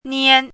“拈”读音
niān
拈字注音：ㄋㄧㄢ
国际音标：niæn˥